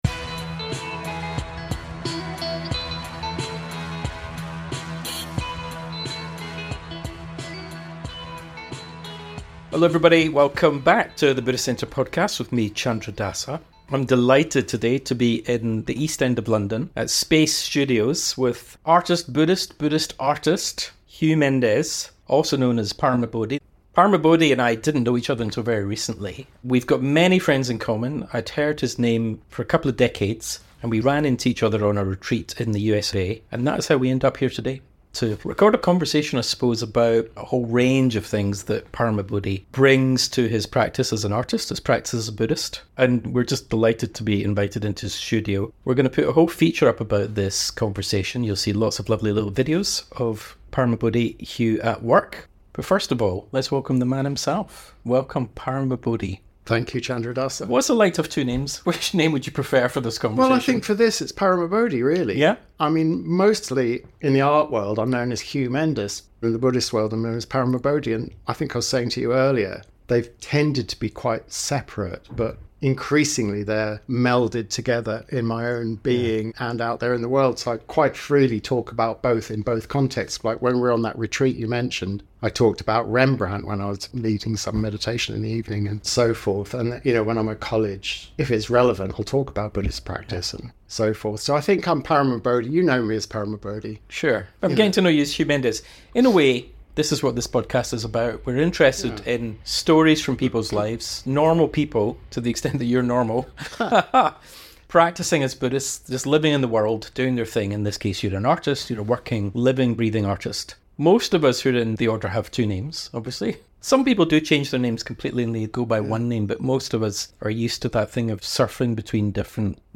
We are sitting in an East End art studio talking about death. The London tube rolls by outside, surfacing momentarily from tunnels dark as Hades, trundling its occupants inexorably on to somewhere. You can hear at intervals its soothing, almost womb-like background rumble and hush as a reminder of sorts